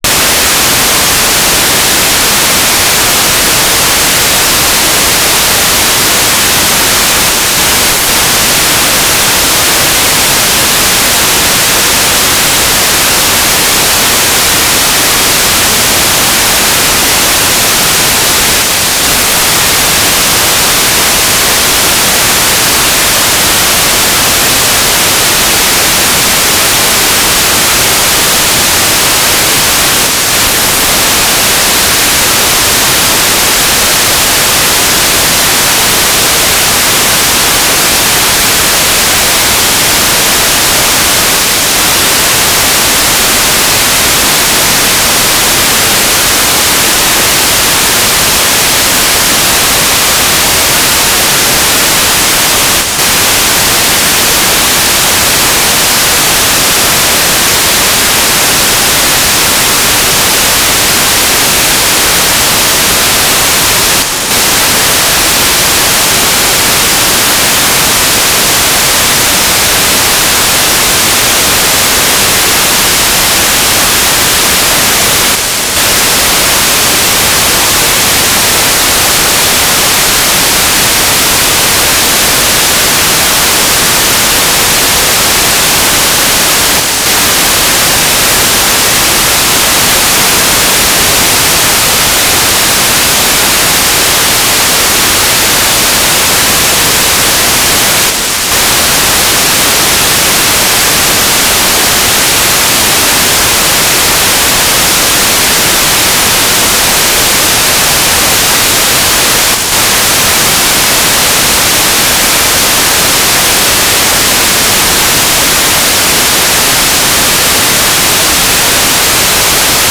"station_name": "Drachten",
"transmitter_description": "Mode U - Transmitter",
"transmitter_mode": "FM",